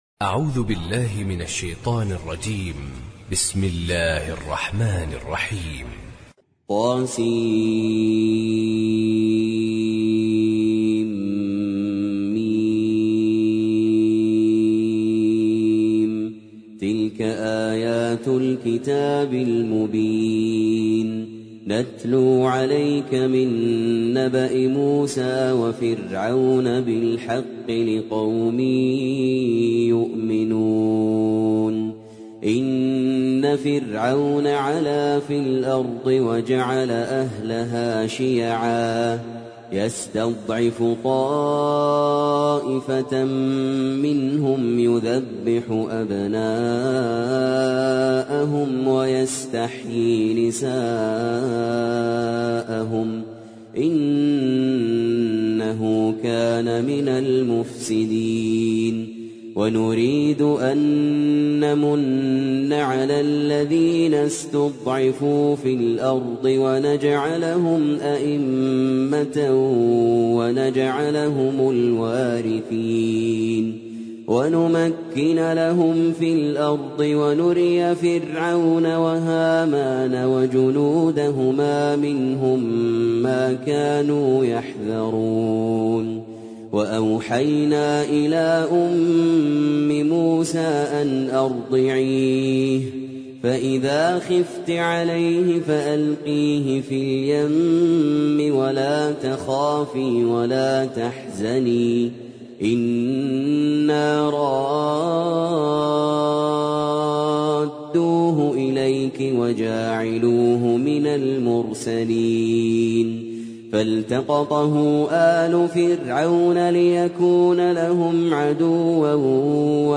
سورة القصص - المصحف المرتل (برواية حفص عن عاصم)
جودة عالية